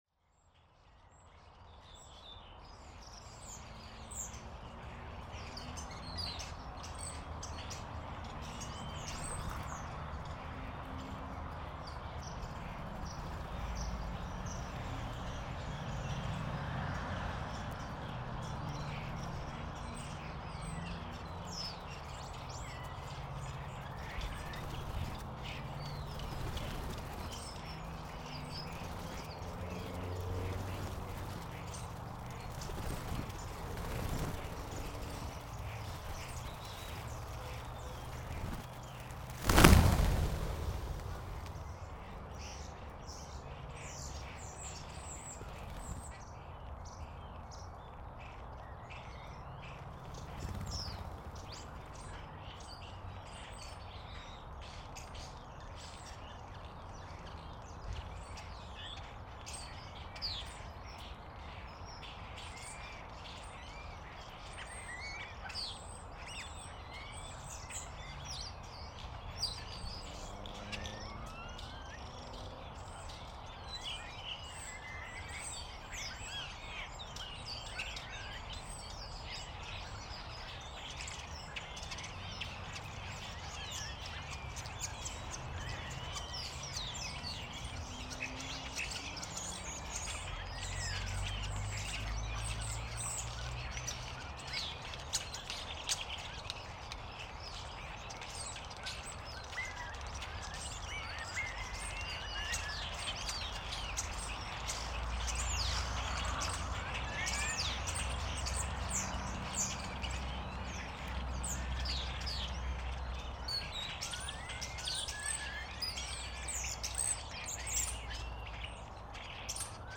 Starling traffic
Most of them are just traffic noises. I am used to feed birds in my garden so they are an ideal sound source and the following recording is one of this feeding day in November. After almost four hours recordings a very big group of Starlings visited my garden. They fly and walk around the microphones. A raven was not far away and frightened most of the starlings when he flew close by.
t354_starling-traffic.mp3